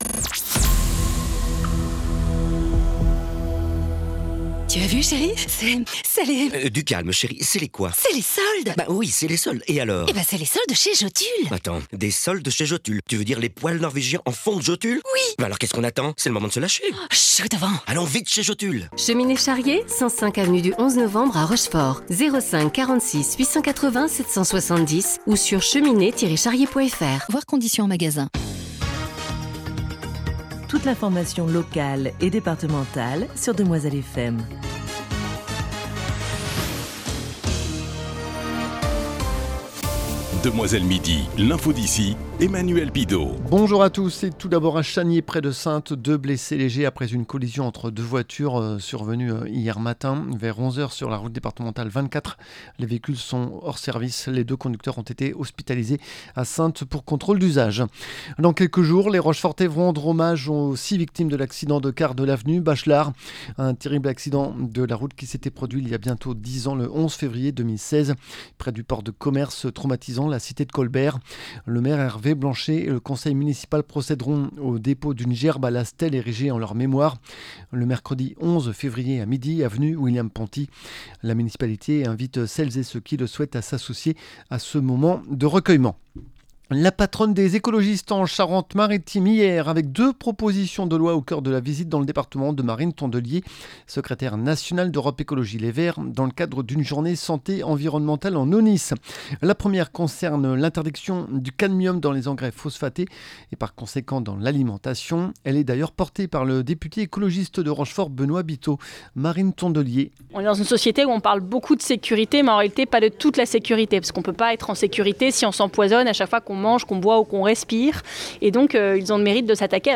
Le journal du 03 Février 2026
La patronne des verts est venue parler protection des captages d’eau potable et cadmium dans l’alimentation. Nous l’entendrons.